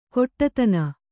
♪ koṭṭatana